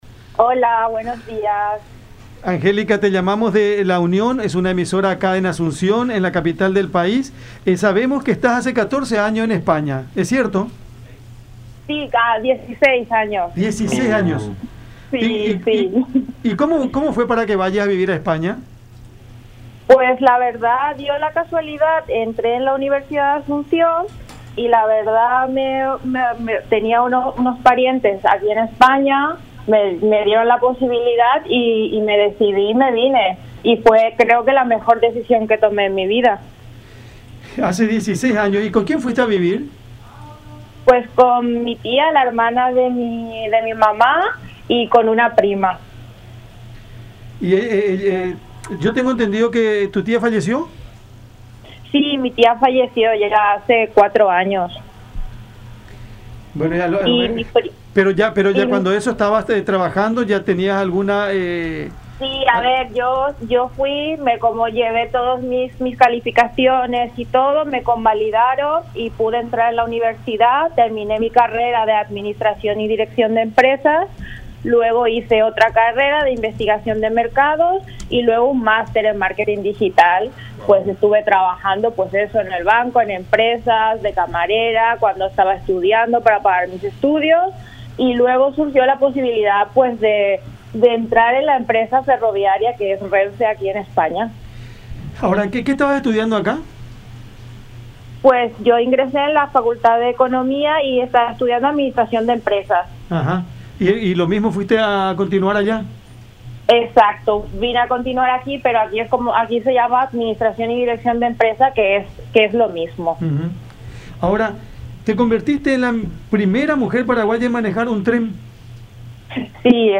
en conversación con el programa Cada Mañana por La Unión